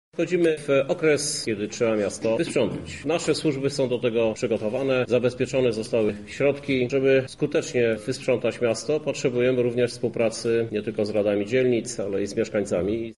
-To jest duże zadanie dla samorządu – mówi prezydent miasta Krzysztof Żuk.